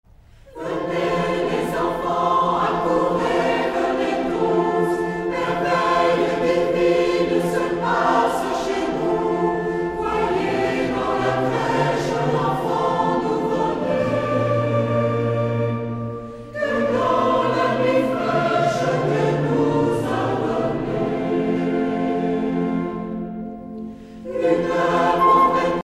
Noël, Nativité
Genre strophique
chorale